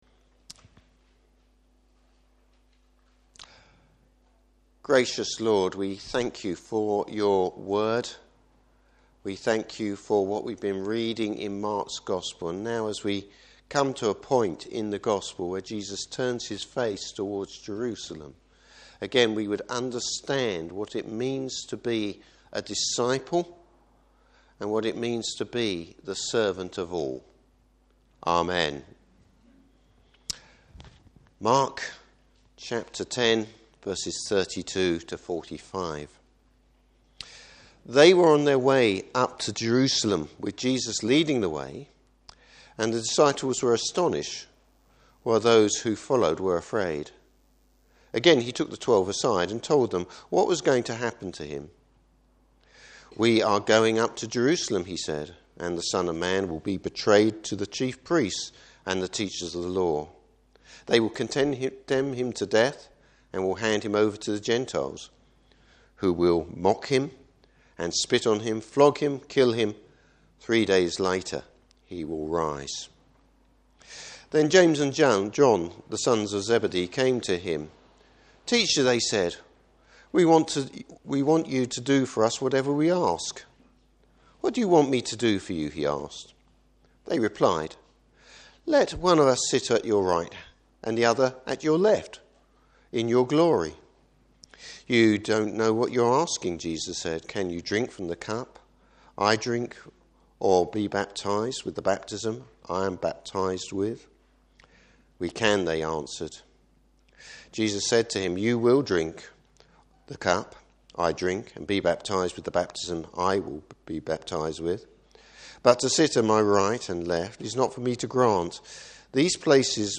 Service Type: Morning Service What true Christian servanthood looks like.